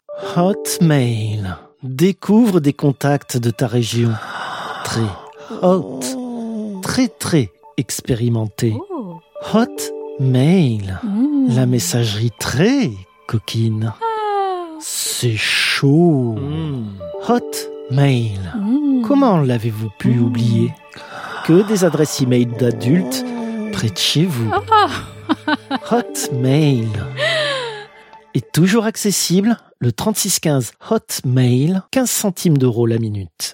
Chroniques › Fausse publicité
[En fond, une musique très lascive tandis que deux voix ont des petits moments complices ponctués de petits rires et de petites exclamations, notification d'e-mail entrant] Hot mail.